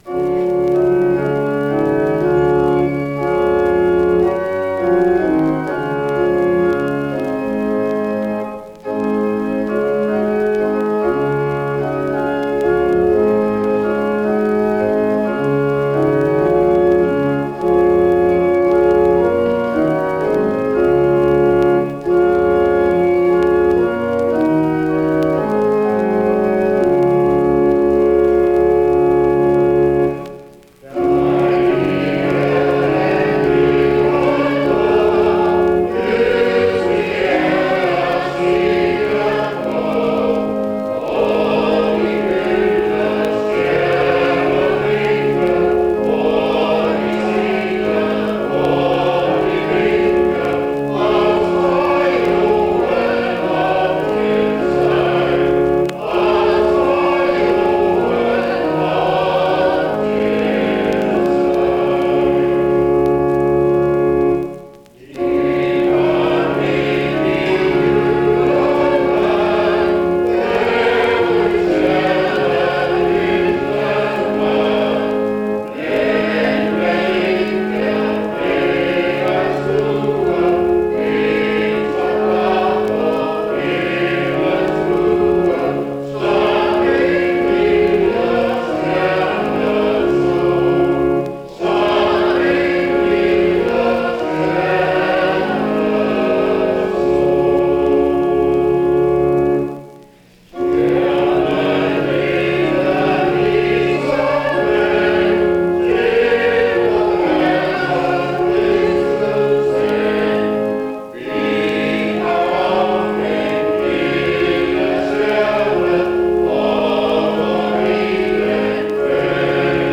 Concert Soprano